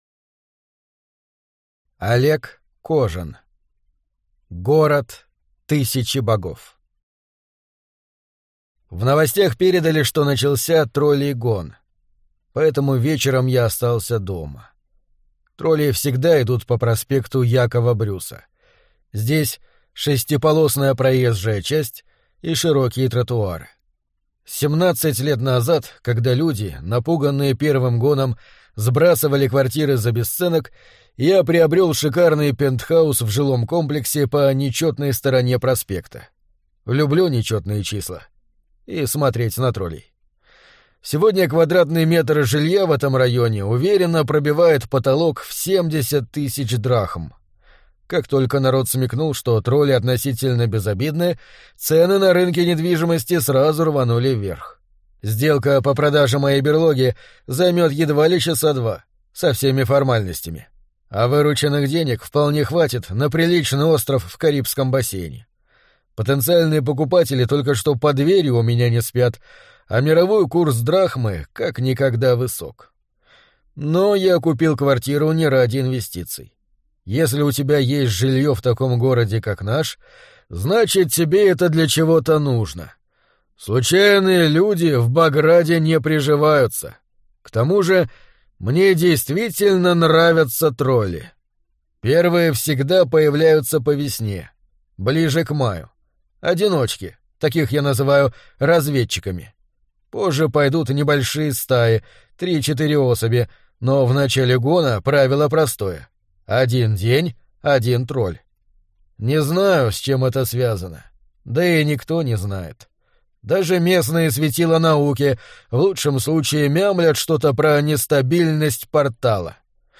Аудиокнига Город тысячи богов | Библиотека аудиокниг